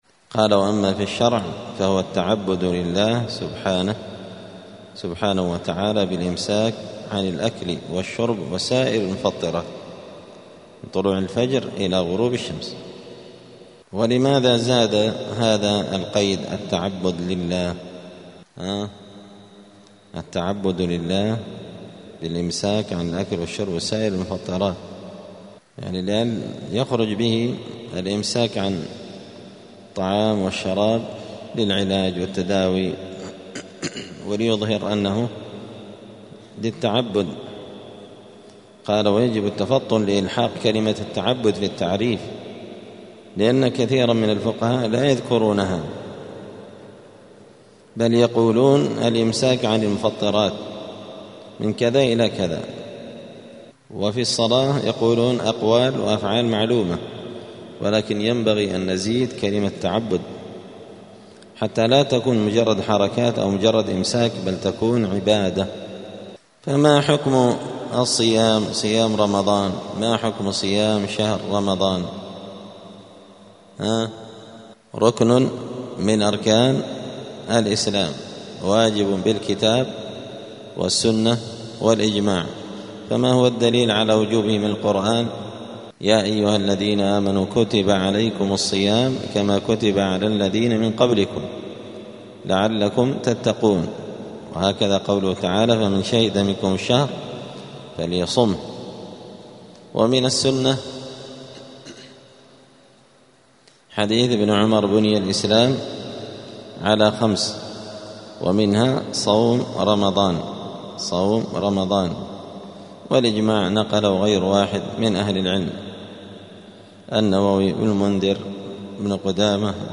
الجمعة 29 شعبان 1446 هــــ | الدروس، مذاكرة لأحكام الصيام | شارك بتعليقك | 35 المشاهدات